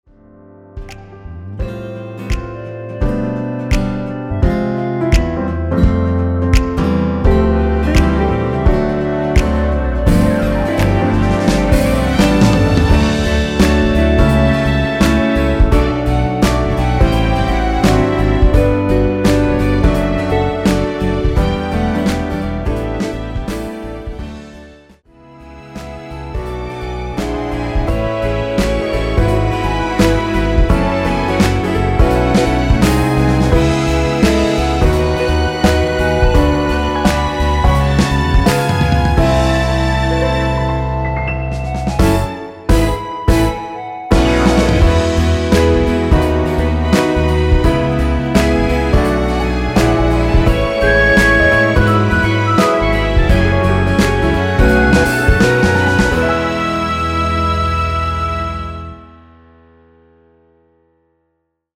엔딩이 페이드 아웃이라 노래 하기 좋게 엔딩을 만들어 놓았습니다.(미리듣기 참조)
원키에서(-1)내린 (짧은편곡) 멜로디 포함된 MR입니다.
음정과 박자 맞추기가 쉬워서 노래방 처럼 노래 부분에 가이드 멜로디가 포함된걸
앞부분30초, 뒷부분30초씩 편집해서 올려 드리고 있습니다.
중간에 음이 끈어지고 다시 나오는 이유는